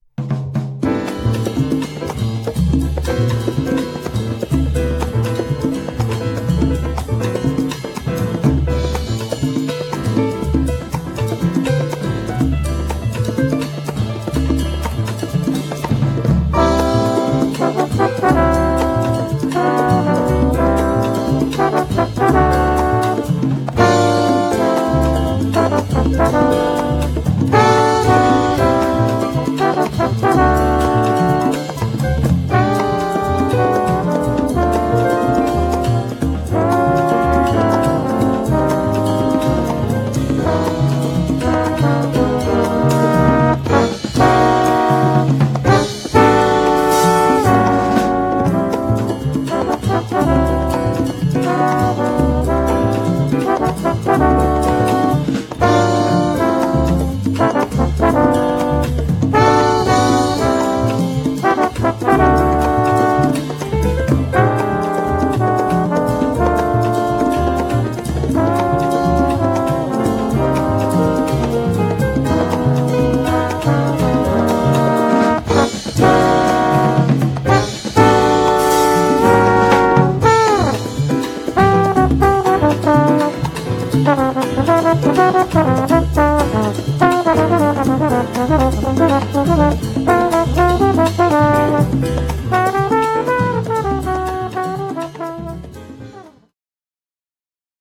trombone • shells
piano
bass
drums